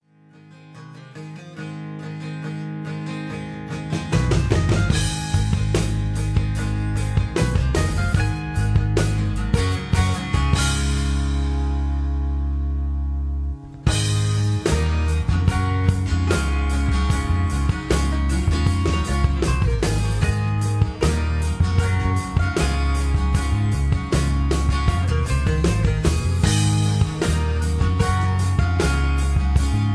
Tags: karaoke , rock and roll